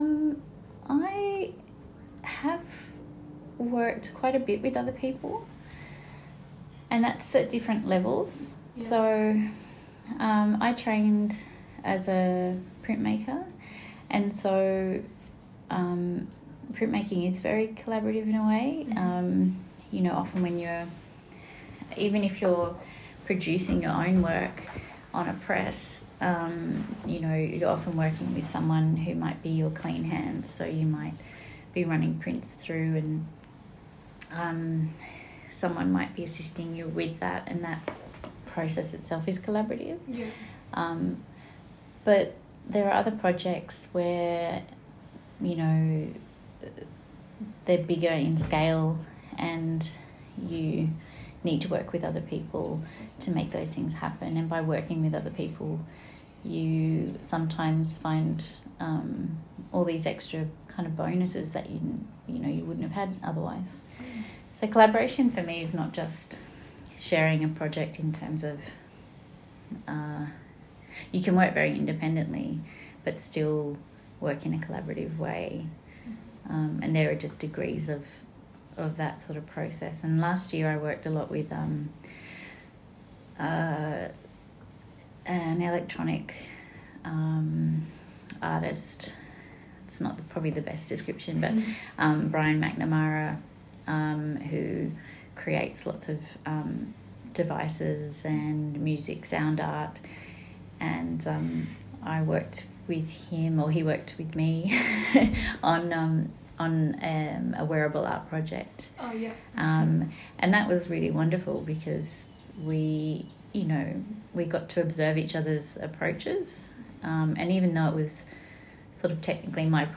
From interview